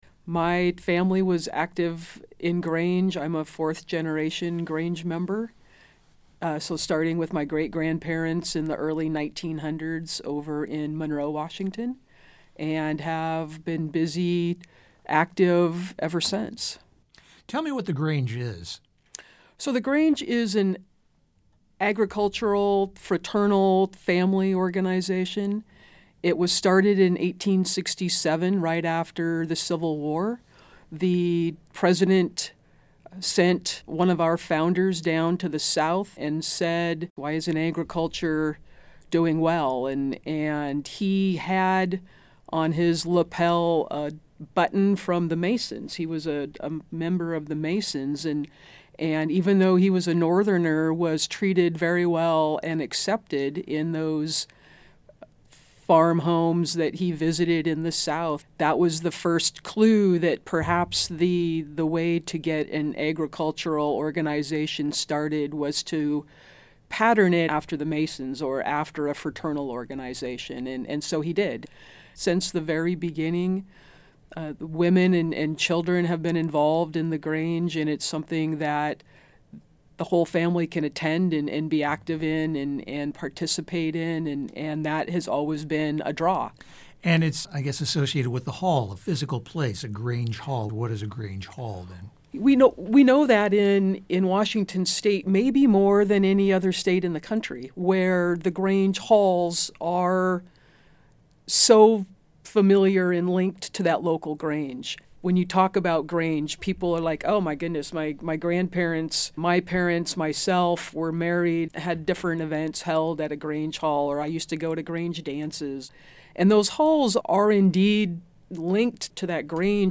Interview with Spokane Public Radio